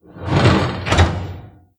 hatch-door-close.ogg